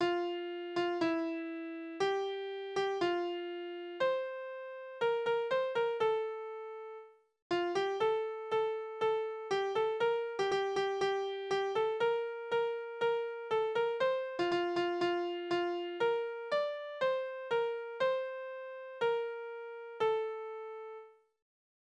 Trinklieder: Branntwein, Branntwein, du edeles Getränk!
Tonart: F-Dur
Taktart: 4/4
Tonumfang: kleine Septime